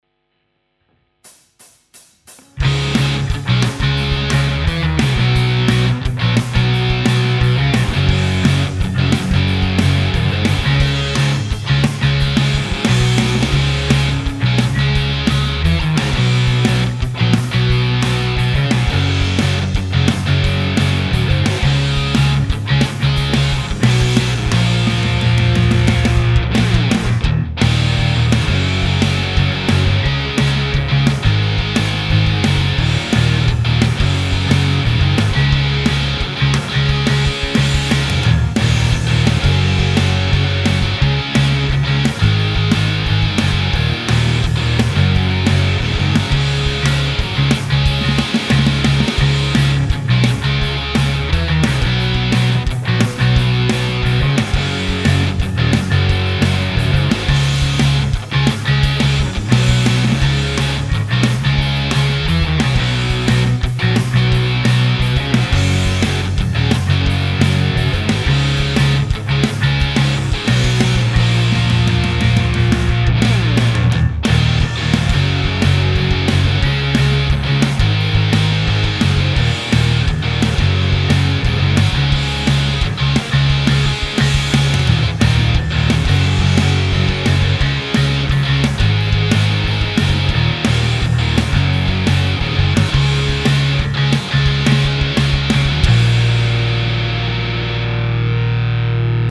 Bassdrum geht im Mix unter
Besteht derzeit aus Gitarre, Bass und Schlagzeug. Wobei die Gitarre links und rechts über 2 unterschiedliche Amps abgebildet werden. Die Snare ist eigentlich genau nach meinem Geschmack und setzt sich schön durch, die Bassdrum geht leider komplett unter bzw. kommt beim Probehören nur auf einer Anlage mit dickem Subwoofer rüber. Auf nem iPad hört man sie praktisch gar nicht.